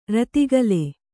♪ ratigale